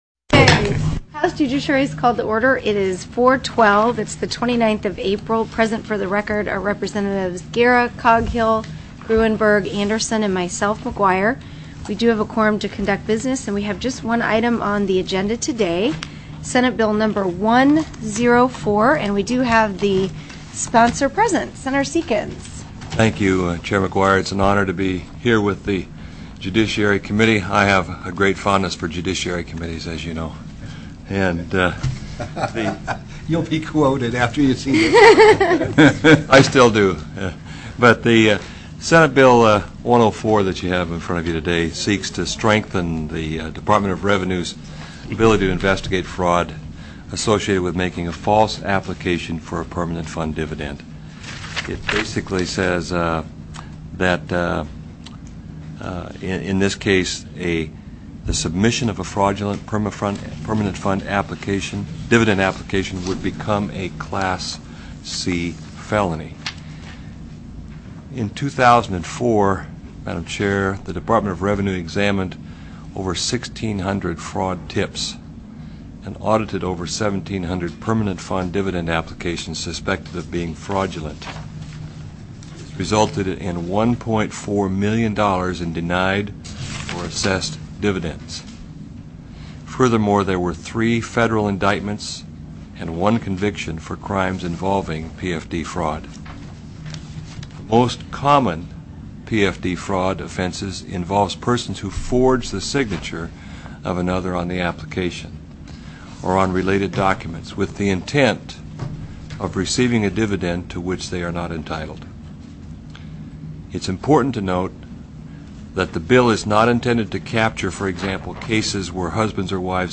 TELECONFERENCED Heard & Held